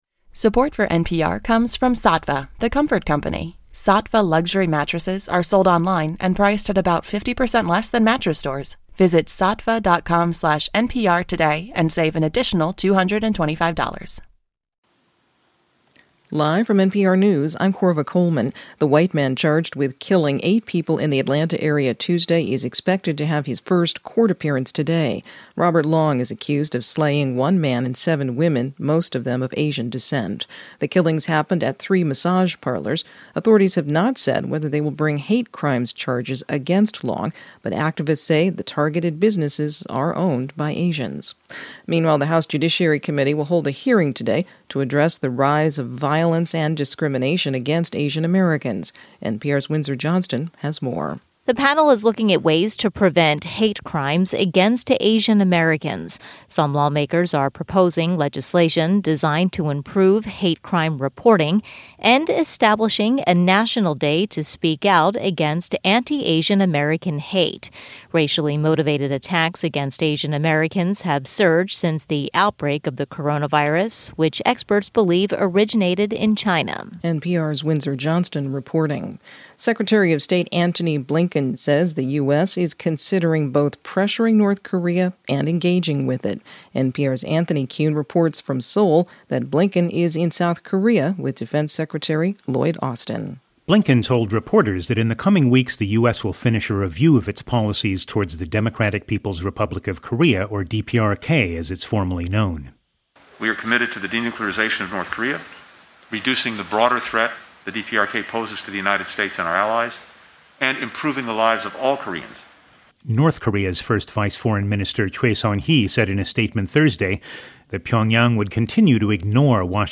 It is quite easy to hear at low bitrate and based on transfer calculators would only take about 15-16 minutes to transfer via the feed.
Its 293KB for a five minute global news report.
So after much experimentation we have managed to create a setup to digest audio content into a format that is both bandwidth conscious and an improvement in sound quality over previous methods and supported by DC3 (Chrome, Firefox and Edge).